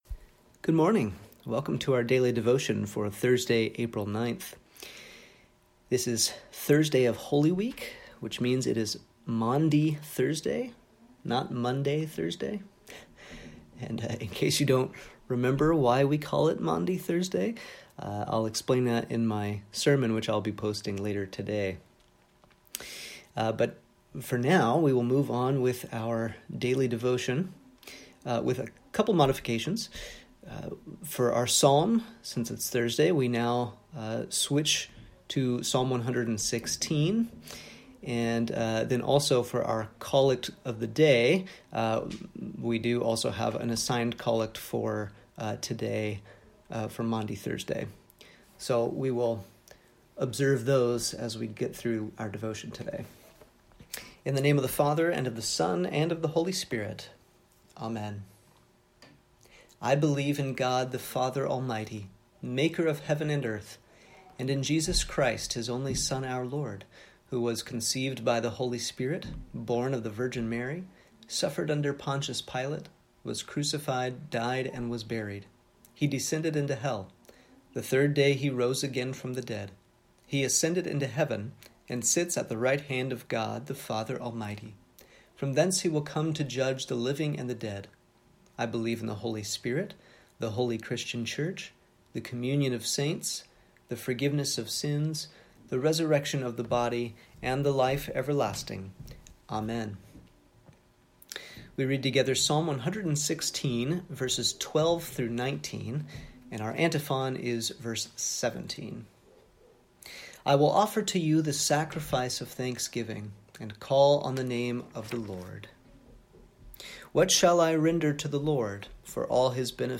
Morning Devotion for Thursday, April 9th